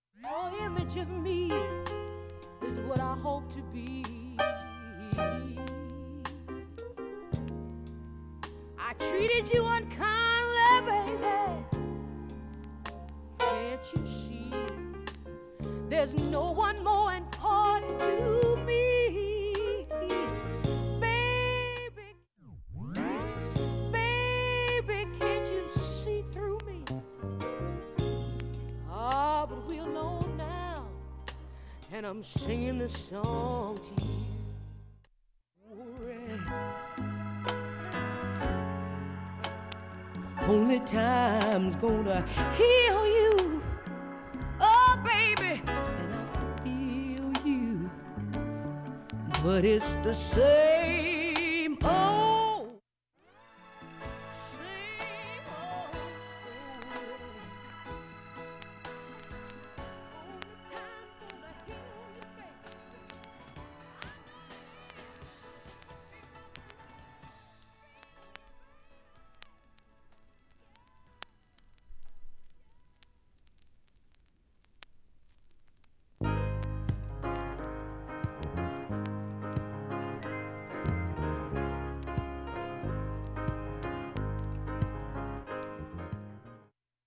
音質良好全曲試聴済み。
３回までのかすかなプツが２箇所
単発のかすかなプツが４箇所